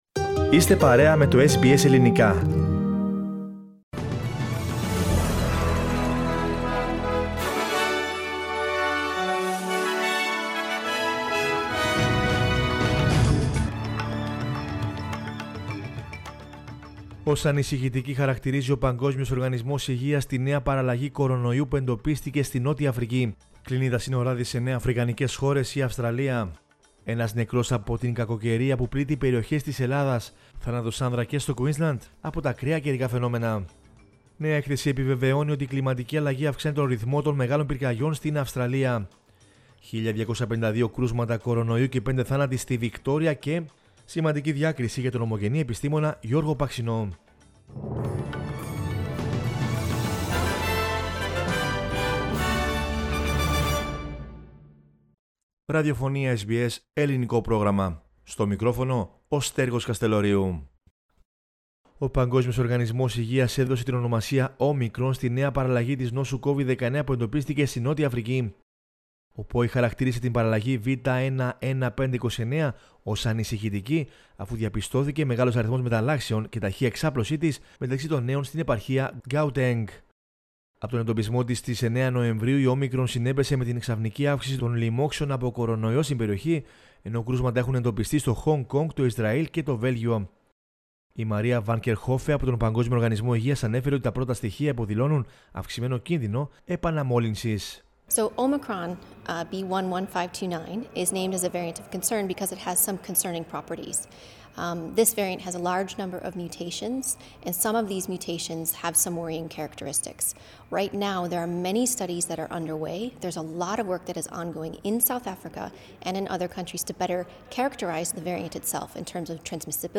News in Greek from Australia, Greece, Cyprus and the world is the news bulletin of Saturday 27 November 2021.